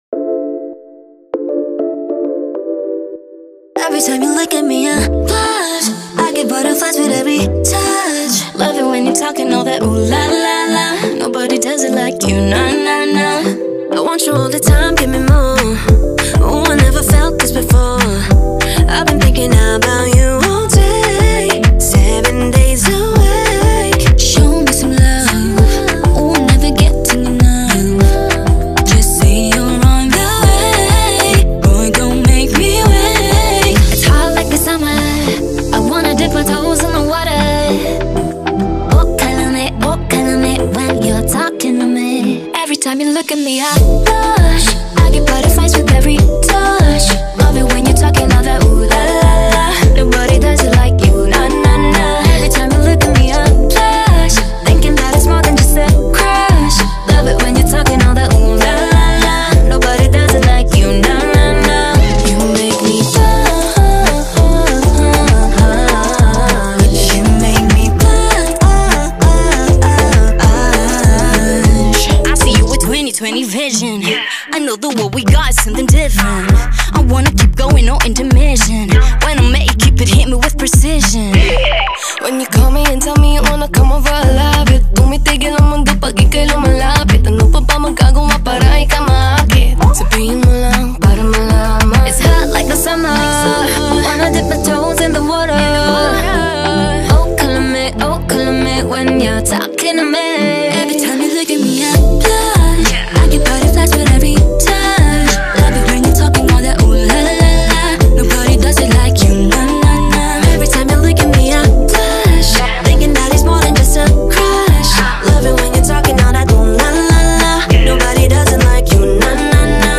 It’s playful, bright, and instantly addictive.